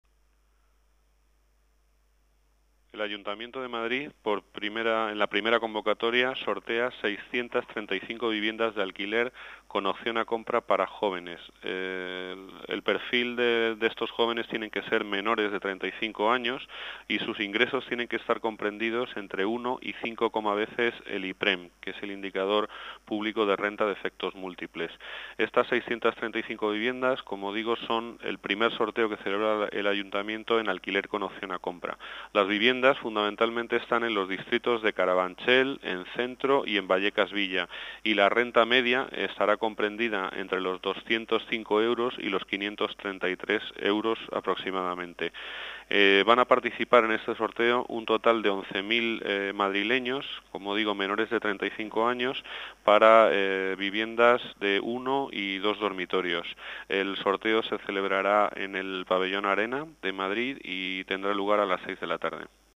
Nueva ventana:El coordinador general en funciones de Vivienda, Juan José de Gracia, habla del perfil de los jóvenes que entran en el sorteo de viviendas de alquiler con opción a compra